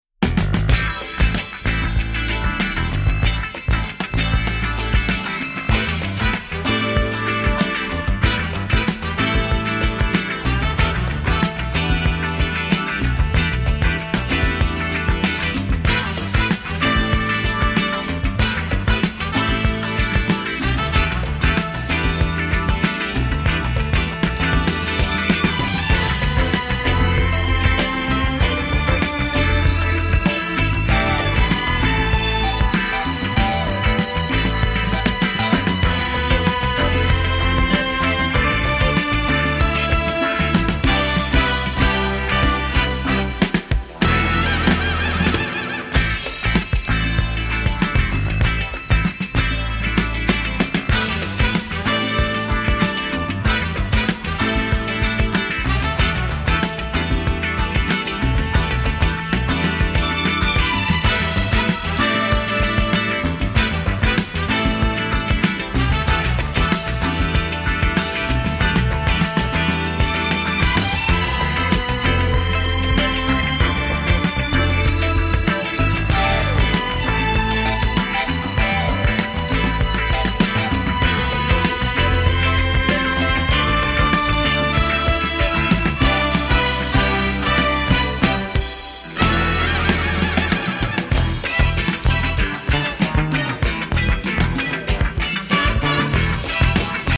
titanic funk, mellow groove and symphonic disco-soul
sweeping, sublime symphonic disco breaks
horn-infused